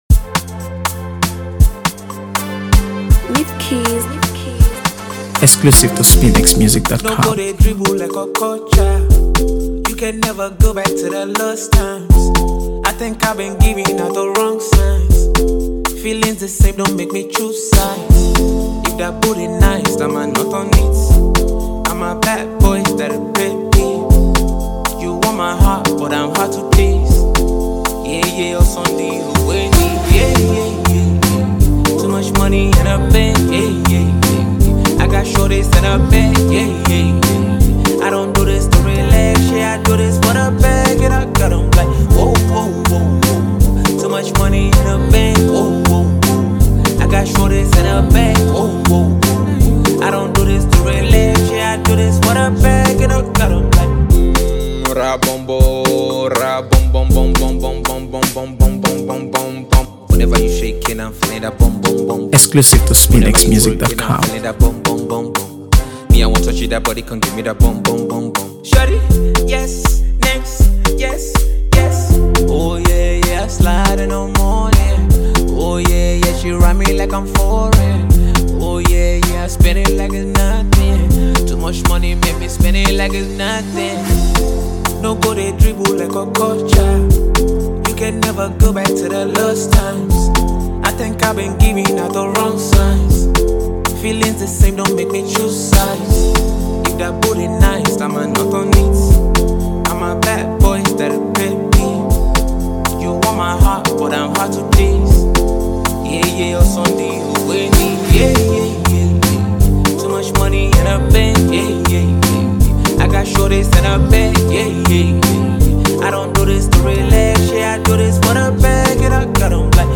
AfroBeats | AfroBeats songs
Blending bold lyricism with catchy melodies
effortlessly fusing rap and melody